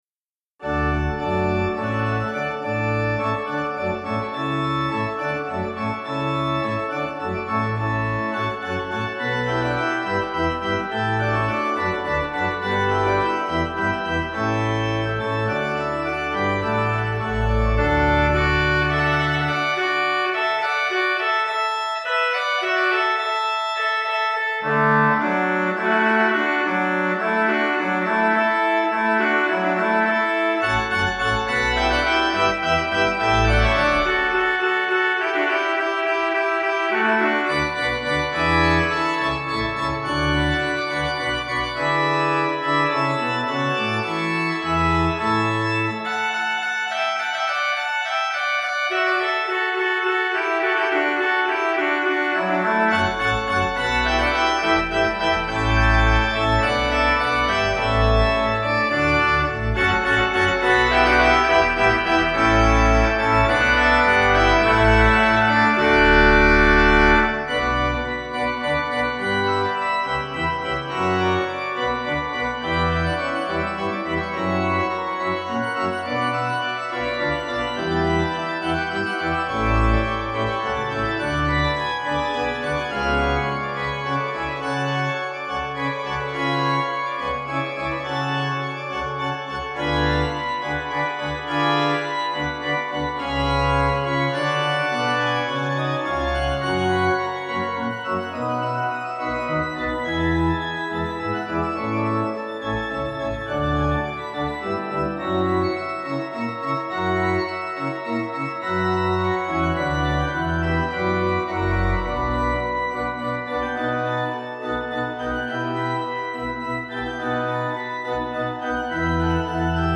Organ
Easy Listening   D 240.5kb